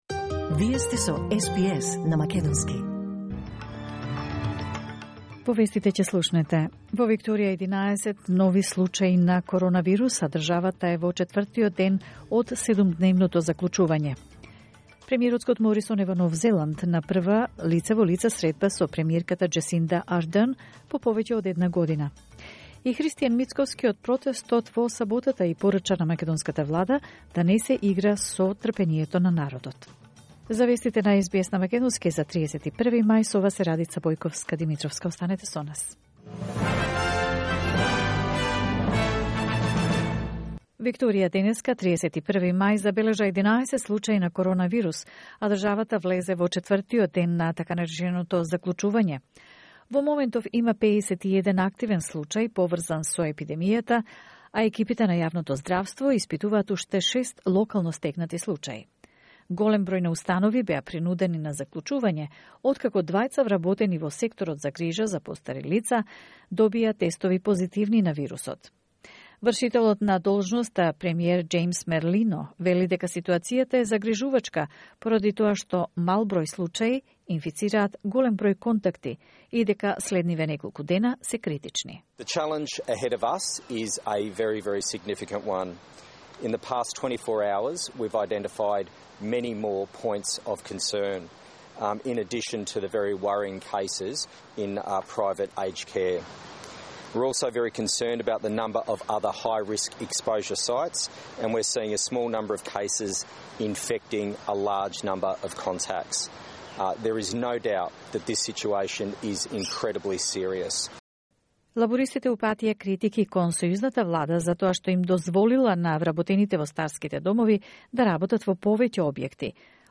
SBS News in Macedonian 31 May 2021